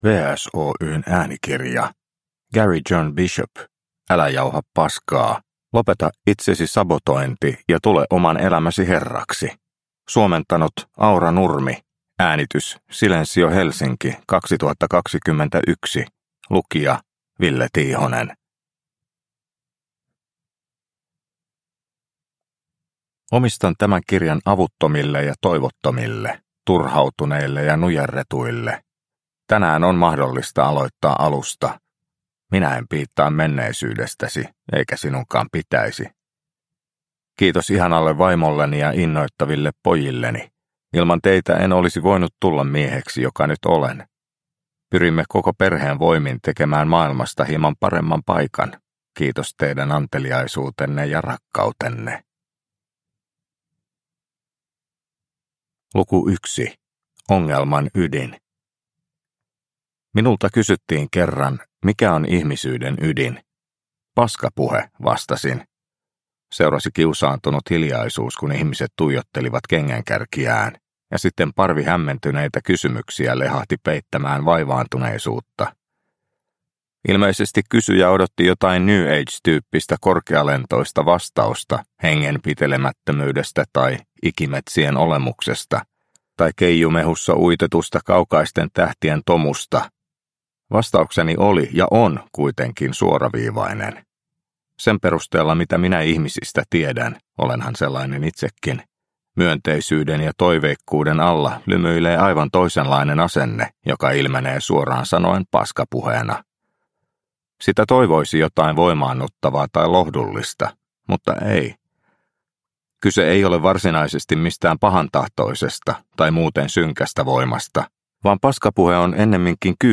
Älä jauha p*skaa – Ljudbok – Laddas ner